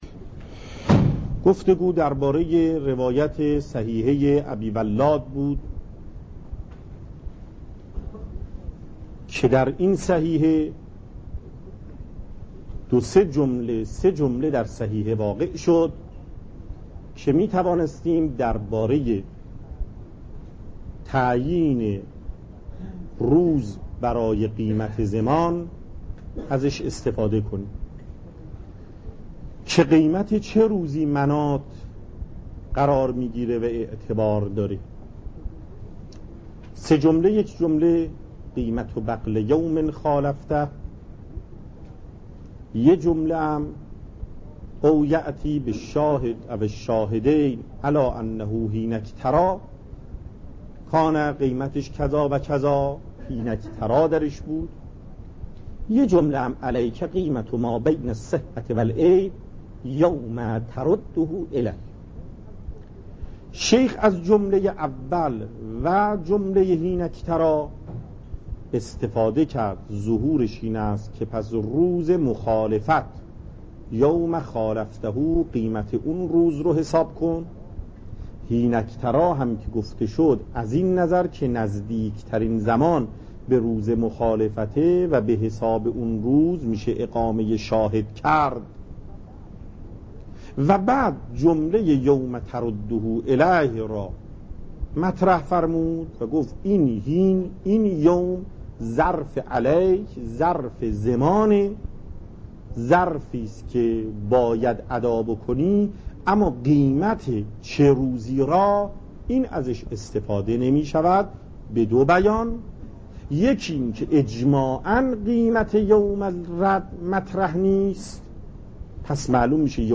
درس مکاسب